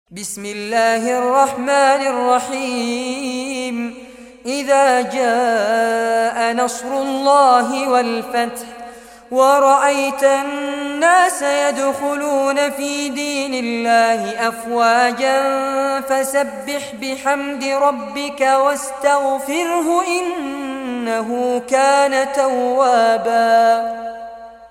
Surah An-Nasr Recitation by Fares Abbad
Surah An-Nasr, listen or play online mp3 tilawat / recitation in Arabic in the beautiful voice of Sheikh Fares Abbad.